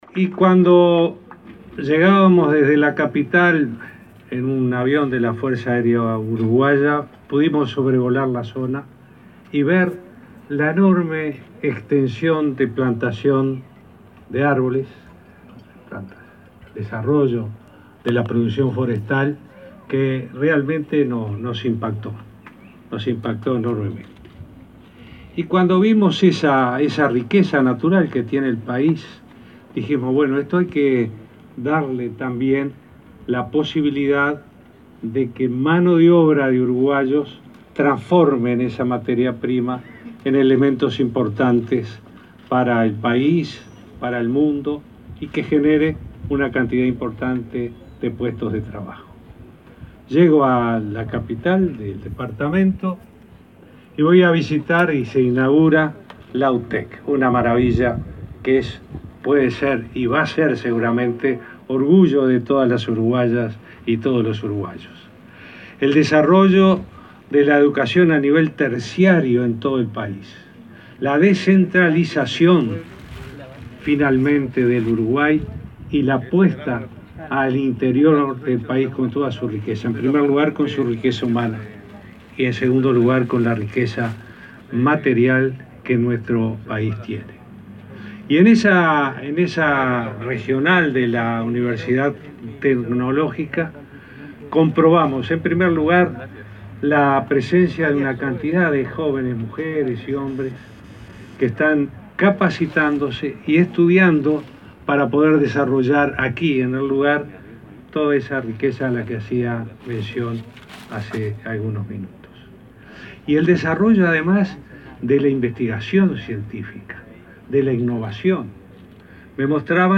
“El Instituto Tecnológico Regional Norte de UTEC es una maravilla que va a ser orgullo de todos los uruguayos”, afirmó el presidente Tabaré Vázquez, en la inauguración del centro de educación terciaria. Afirmó que el desarrollo de la investigación científica y de la innovación son generadores de trabajo para el futuro que representará el comercio electrónico e industrial.